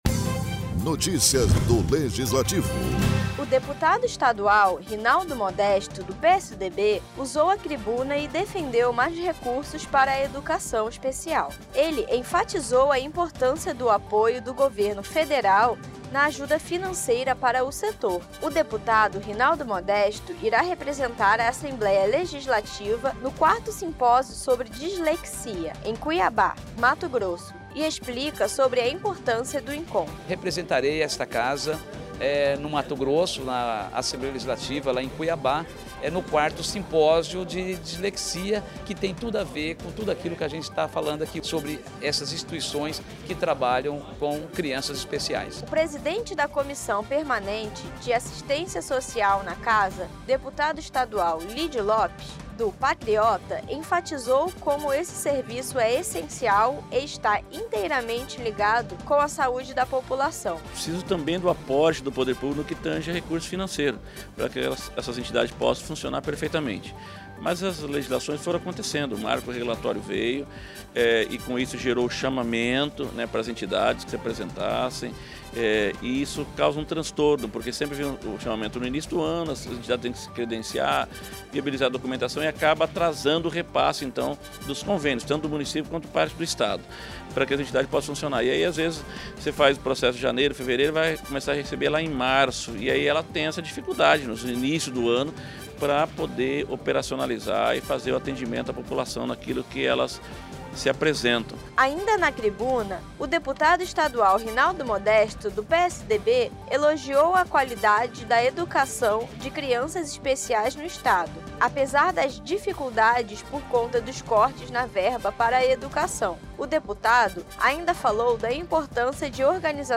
O deputado Estadual Rinaldo Modesto, do PSDB, usou a tribuna nesta quinta-feira, apara defender mais recursos para a educação especial.